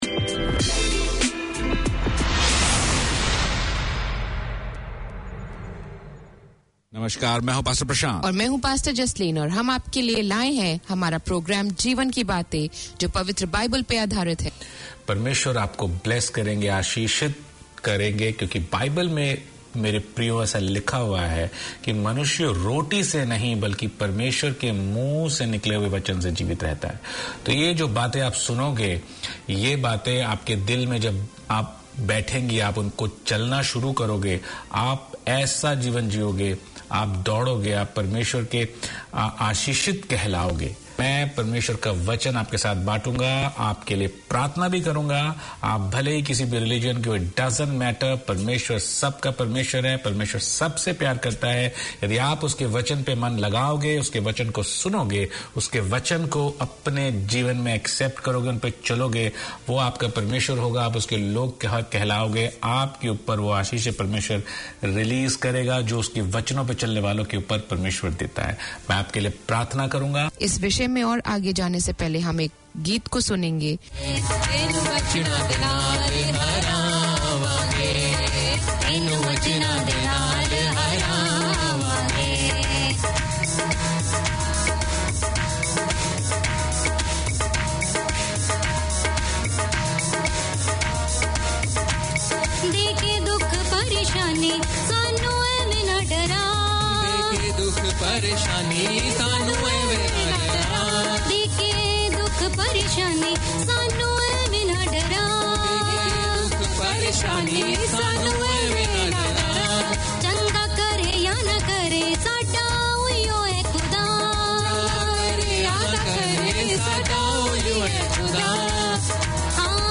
Community Access Radio in your language - available for download five minutes after broadcast.
The programme showcases the history, traditions and festivals of India and Fiji through storytelling and music, including rare Fiji Indian songs.